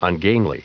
Prononciation du mot ungainly en anglais (fichier audio)
Prononciation du mot : ungainly